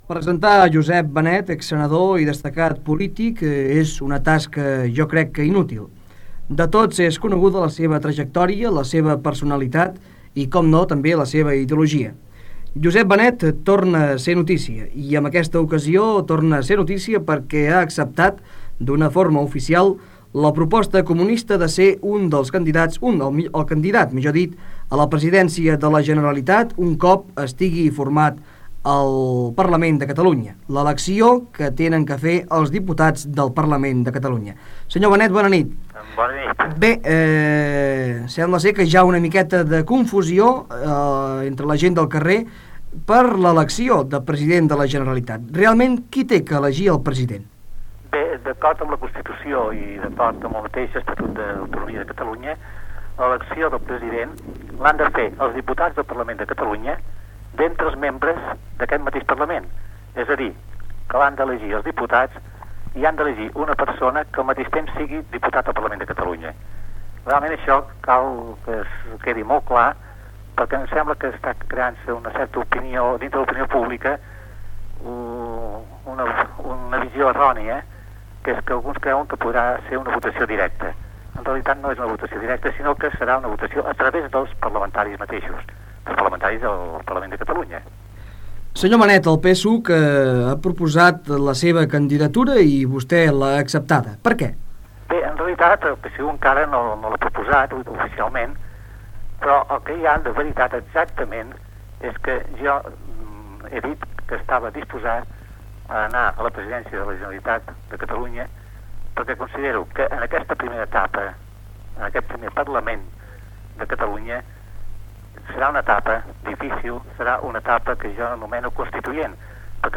Entrevista al polític Josep Benet possible candidat a la Presidència de la Generalitat de Catalunya, a proposta del Partit Socialista Unificat de Catalunya
Informatiu